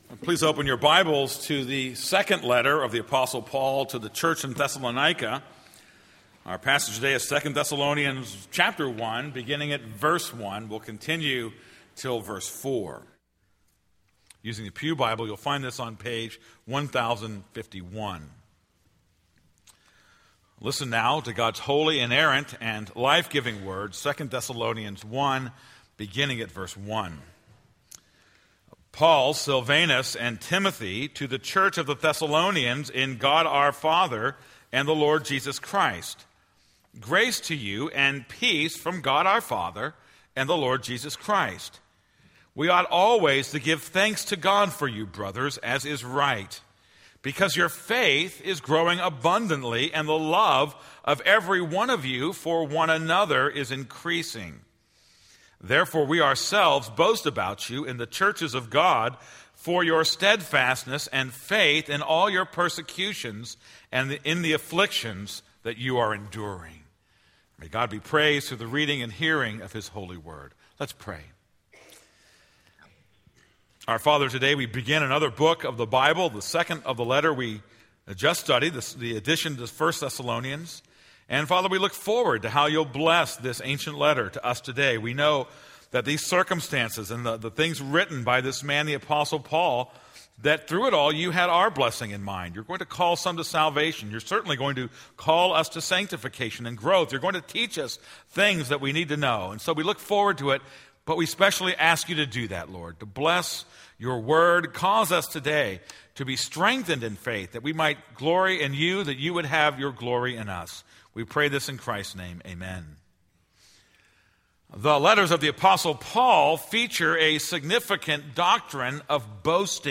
This is a sermon on 2 Thessalonians 1:1-4.